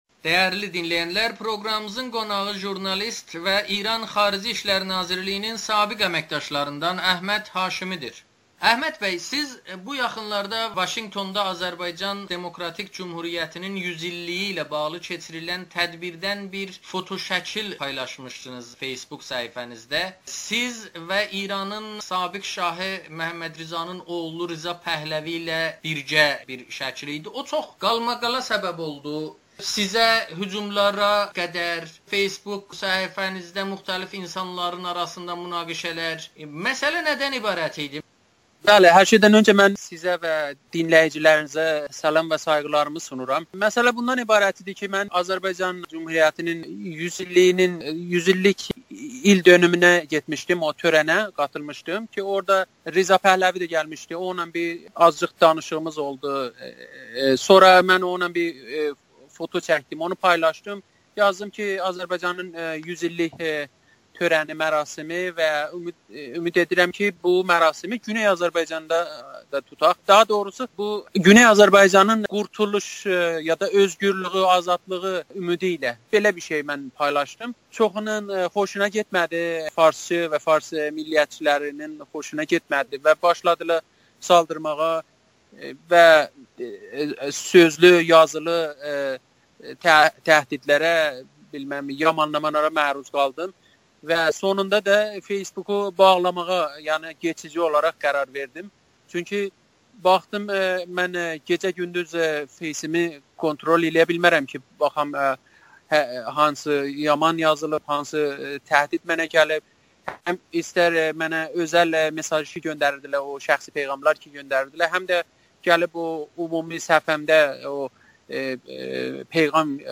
Xaricdəki İran icmalarında düşüncə azadlığı yoxdur [Audio-Müsahibə]